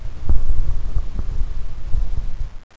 1 channel
magma2.wav